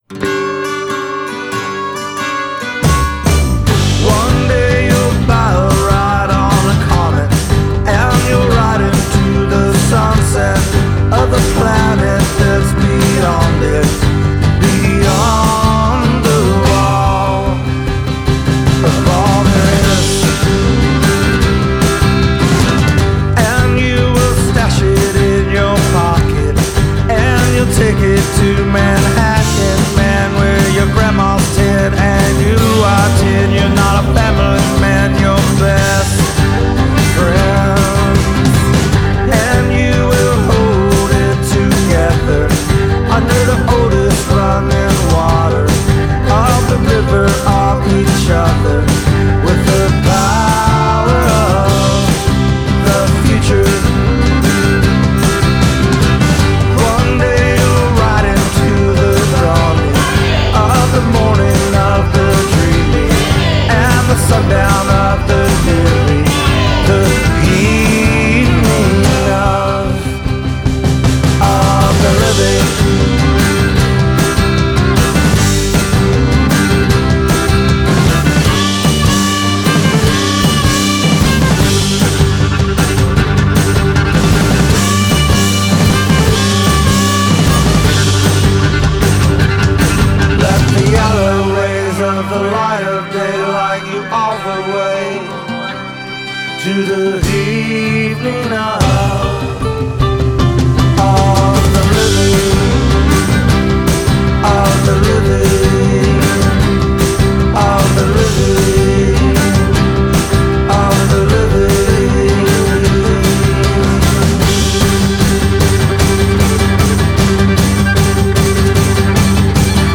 The opening title song
is cheerful Americana